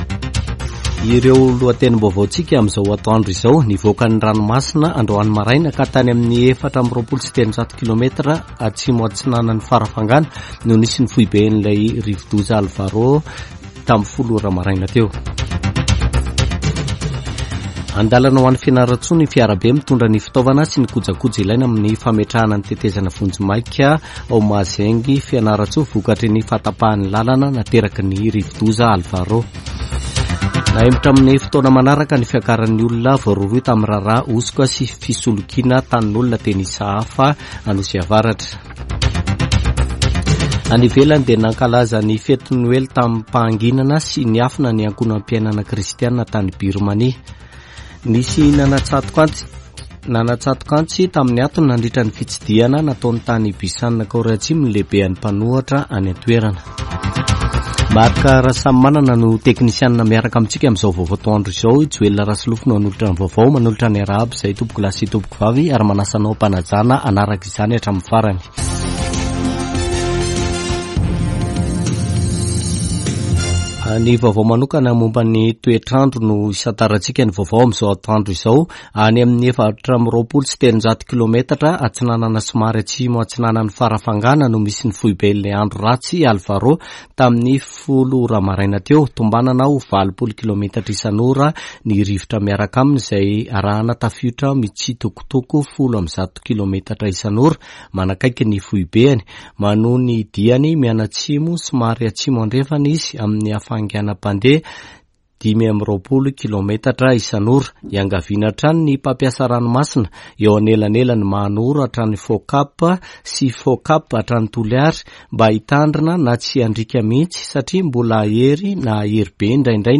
[Vaovao antoandro] Alarobia 3 janoary 2024